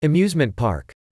9 amusement park (n) /əˈmjuːzmənt pɑːrk/ Công viên giải trí